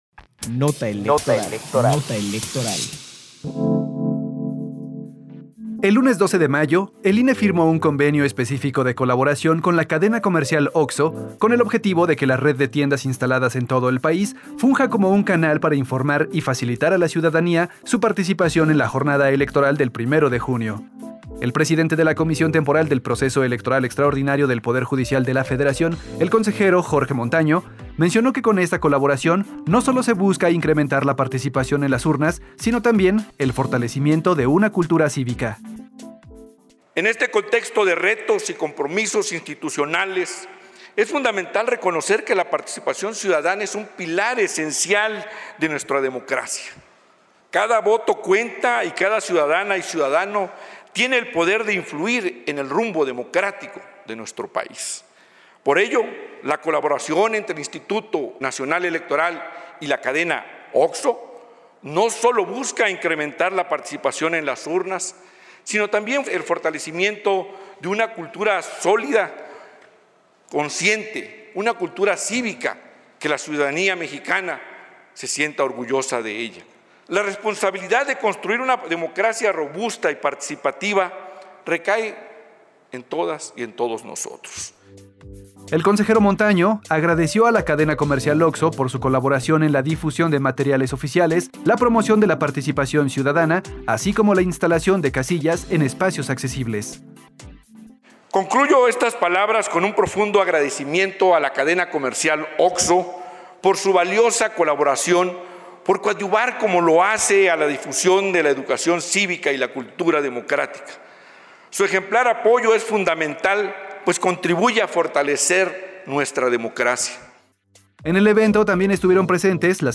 Nota de audio sobre la firma de convenio entre el INE y la cadena OXXO, 12 de mayo de 2025